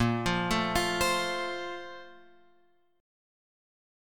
A# Major 7th Suspended 2nd